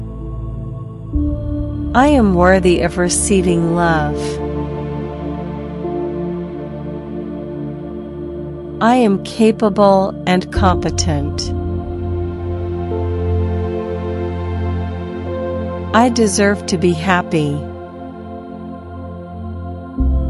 Self Worth Audio Affirmations
2. Listen to the calming music as you repeat the positive affirmations about self worth.